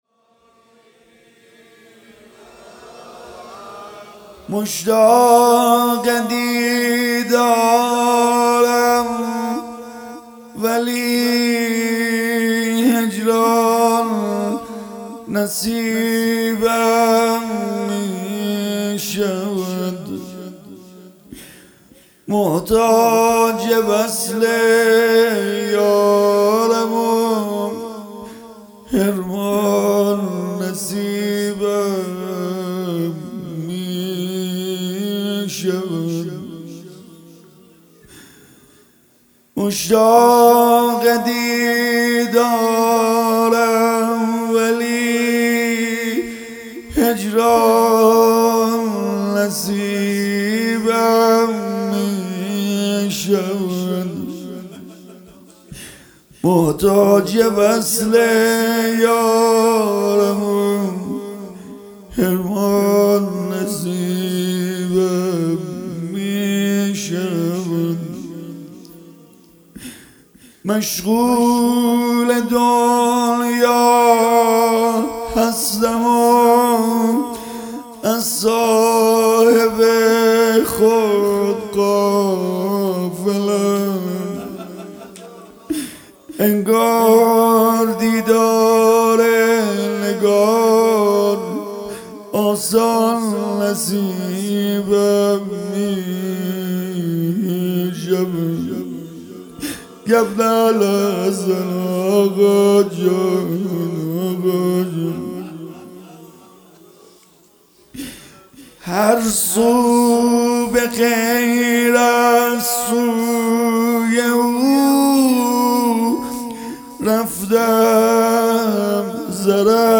مراسم مناجات ماه مبارک رمضان 1445